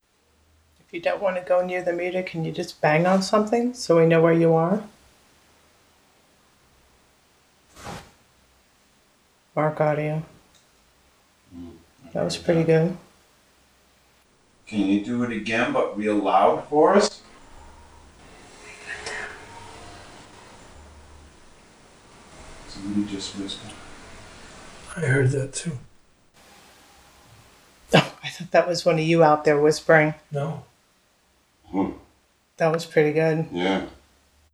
A KNOCK, THEN…….” THINK I CAN” VOICE ISOLATED